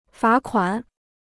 罚款 (fá kuǎn): to fine; penalty.